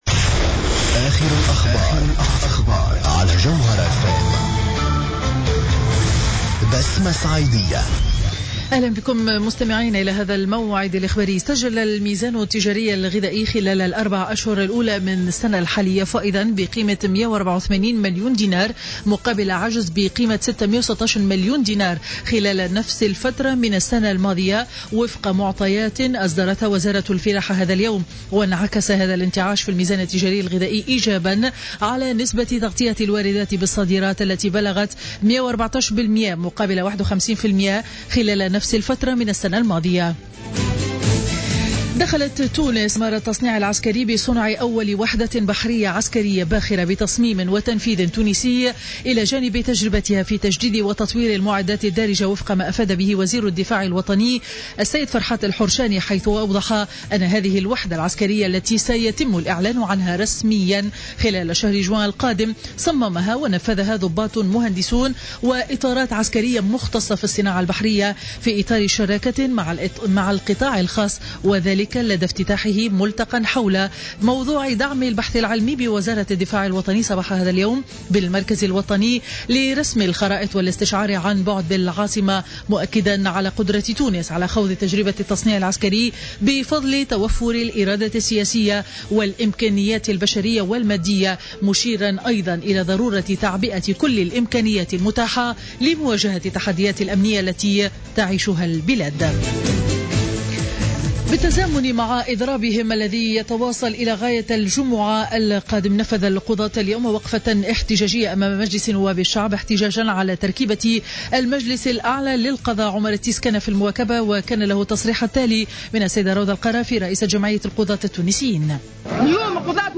نشرة أخبار منتصف النهار ليوم 13 ماي 2015